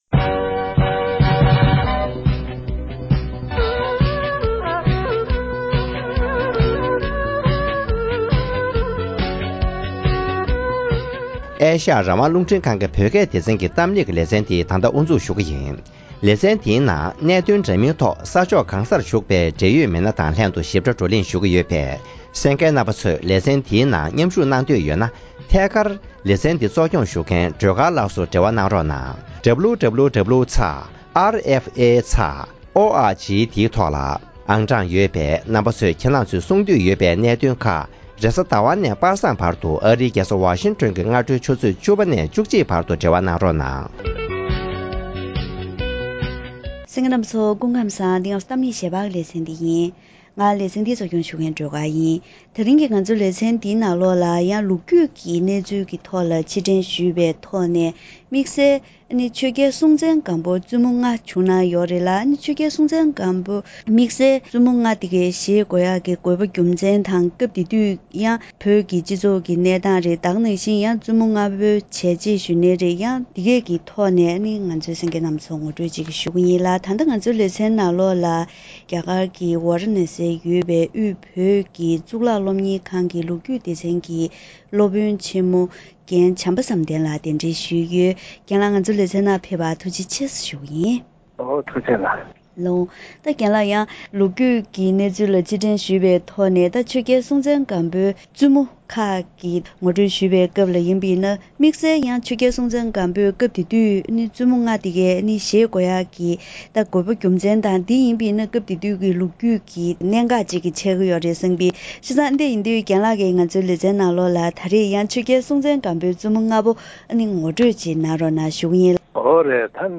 དེ་རིང་གི་གཏམ་གླེང་ཞལ་པར་ལེ་ཚན་ནང་བོད་ཀྱི་ལོ་རྒྱུས་ཐོག་ཆོས་རྒྱལ་སྲོང་བཙན་སྒམ་པོར་བཙུན་མོ་ལྔ་བྱུང་ཡོད་པ་ཡོངས་སུ་གྲགས་ཤིང་།